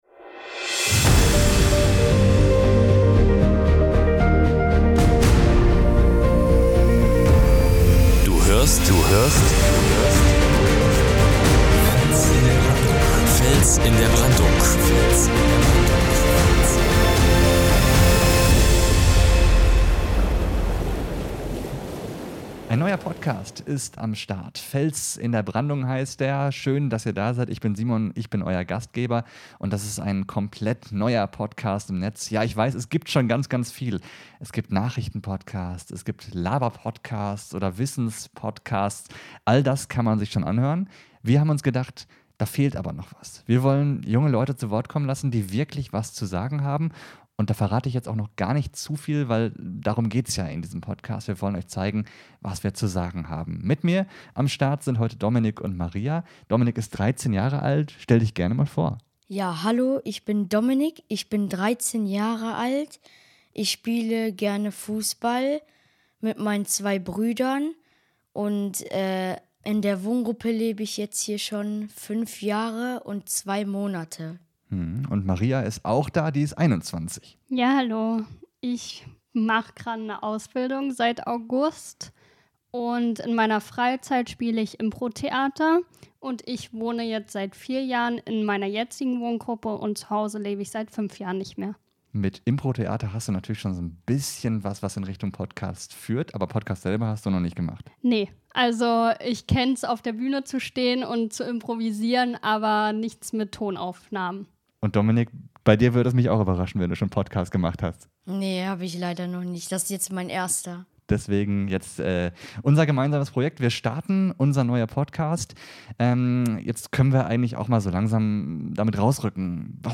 Radio Hitwave – Interview mit Brings auf dem Kirchentag
Das Interview fand auf dem Kirchentag statt. Dort ging es vor allem über den Grund für den Auftritt und um die Band selber.